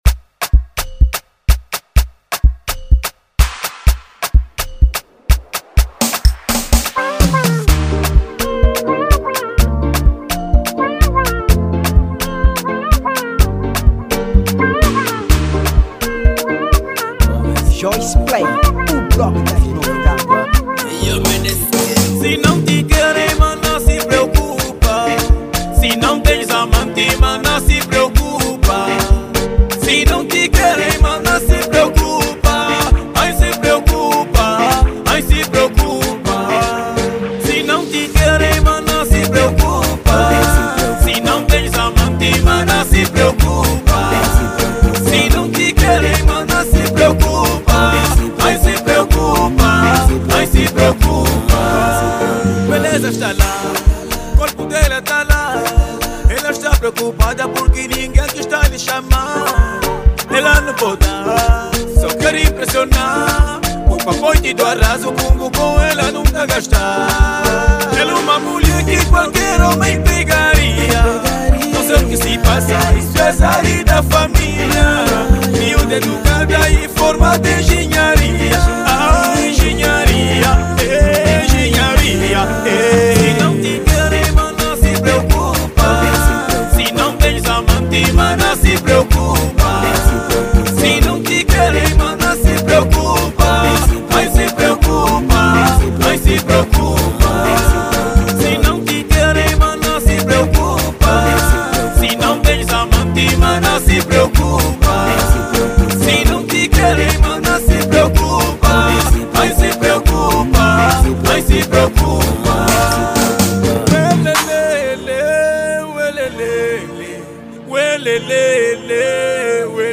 | Afro house